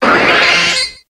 Grito de Staryu.ogg
Grito_de_Staryu.ogg